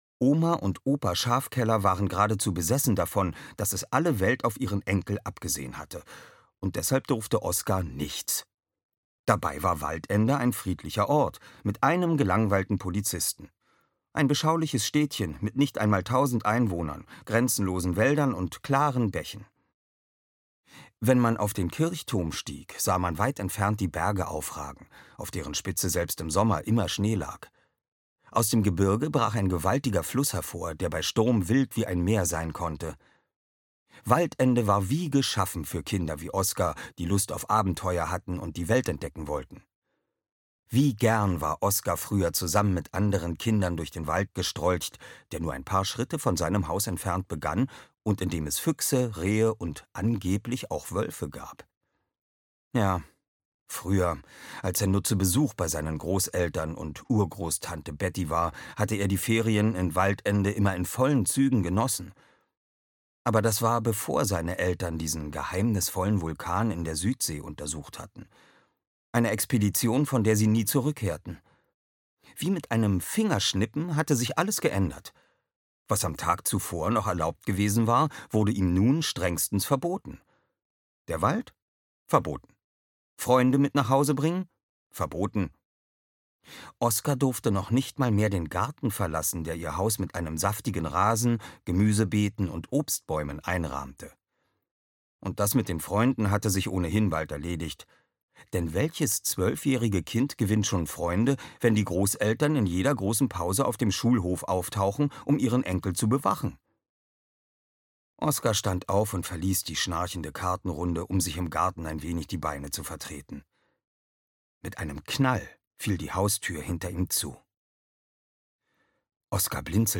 Die Legende von Drachenhöhe 1: Plötzlich Drachentöter! - Frank Schmeißer - Hörbuch